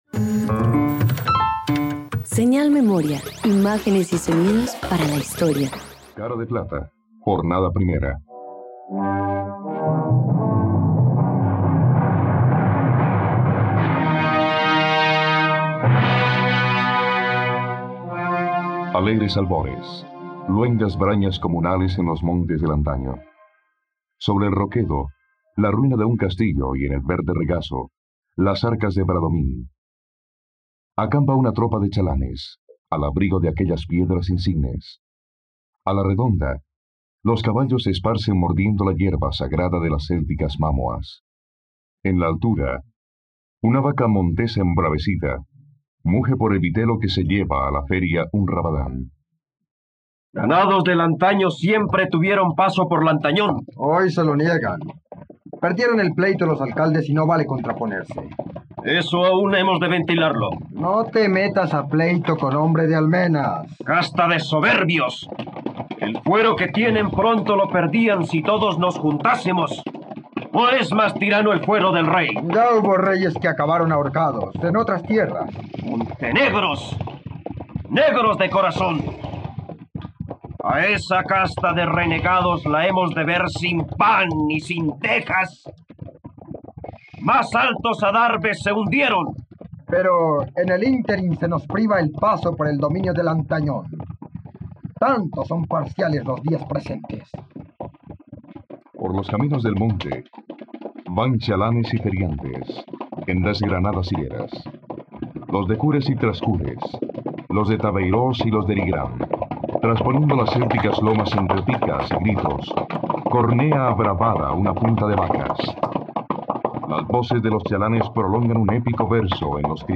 Cara de plata - Radioteatro dominical | RTVCPlay
Los conflictos familiares y religiosos abundan en la adaptación radiofónica de la apasionante obra “Cara de plata” del novelista Ramón del Valle-Inclán. Una amarga sátira del autor hacia la sociedad española de principios del siglo XX.